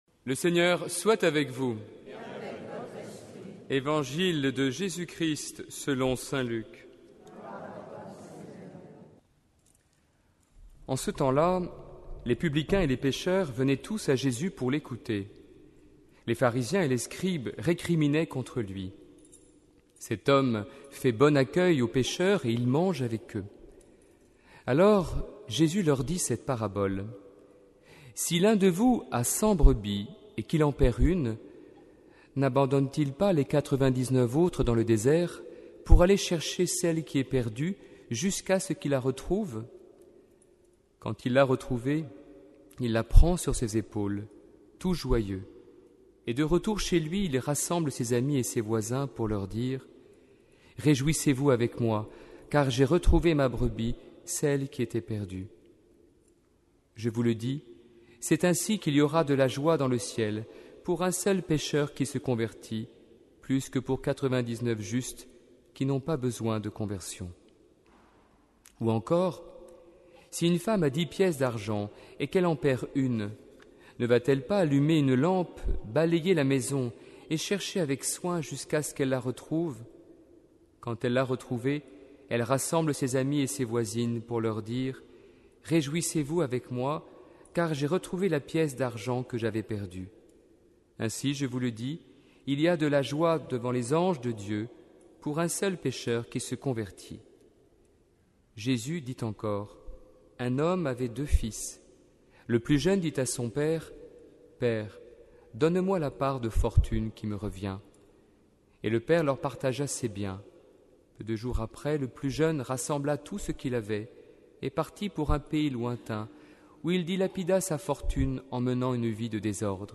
Évangile de Jésus-Christ selon Saint-Luc avec l'homélie